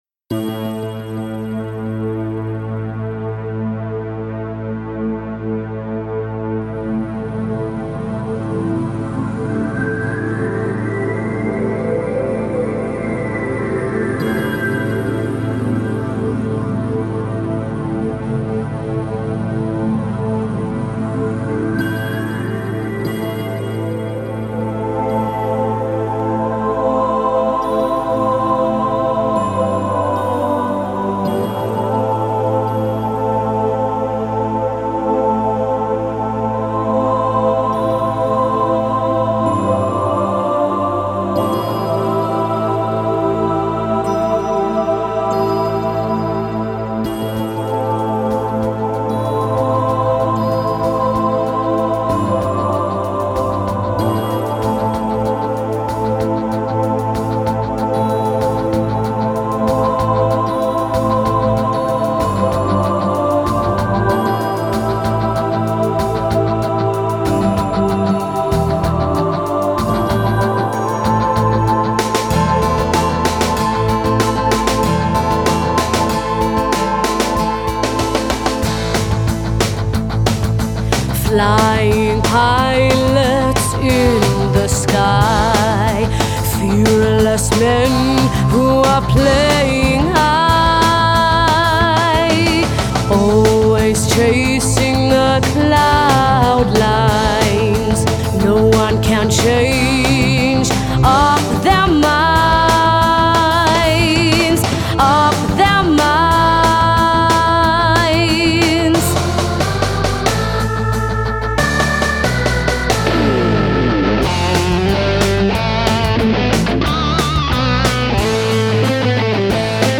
Ηλεκτρική Κιθάρα
Ακουστική Κιθάρα